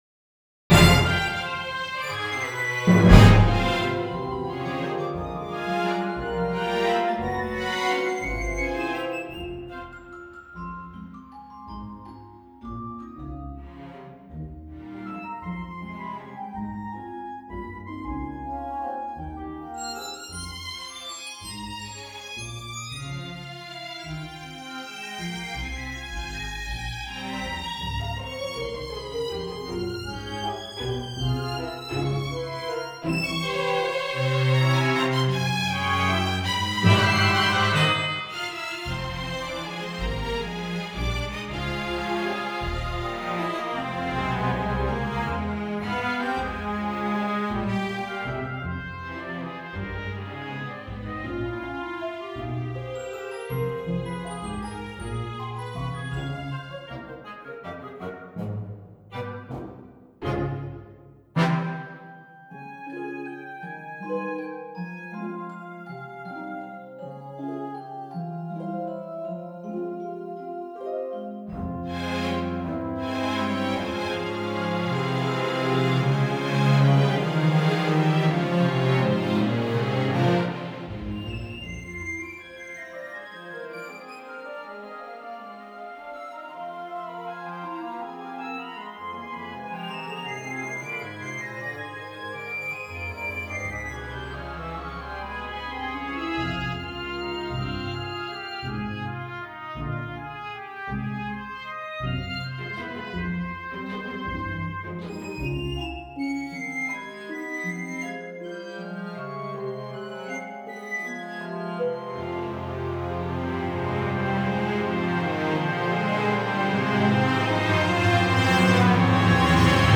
Ballet music
For a large Symphony Orchestra